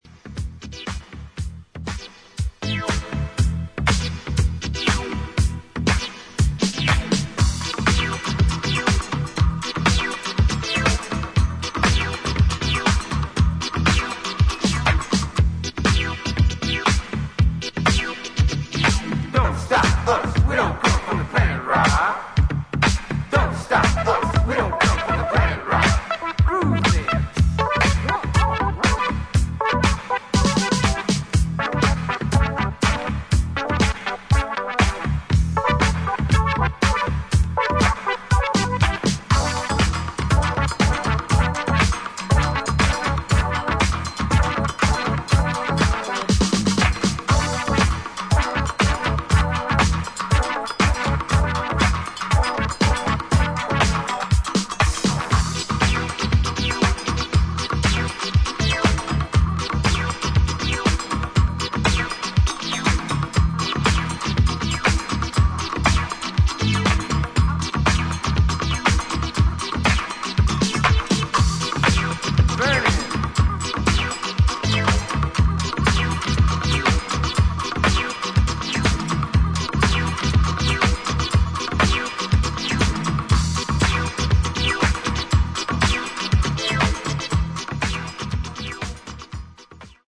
計3トラックをフロア仕様にエディットしたDJユース盤